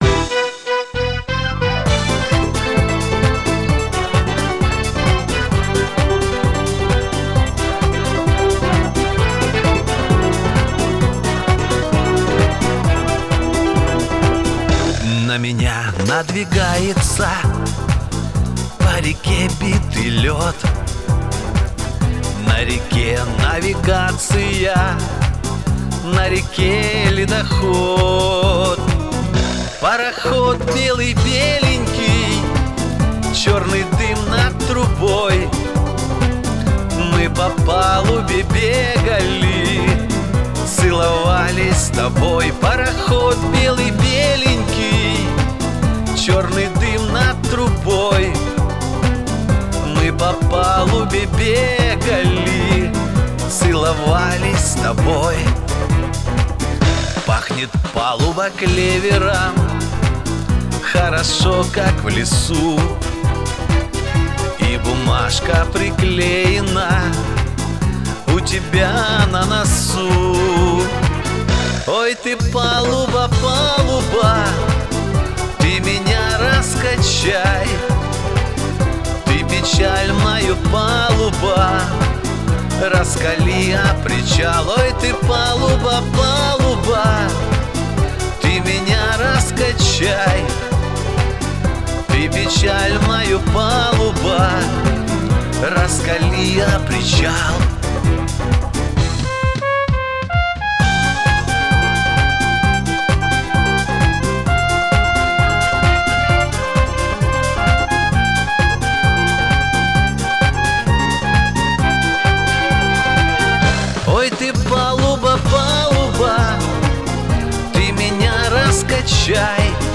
поёт тише и мягче